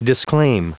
Prononciation du mot disclaim en anglais (fichier audio)
Prononciation du mot : disclaim